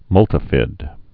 (mŭltə-fĭd)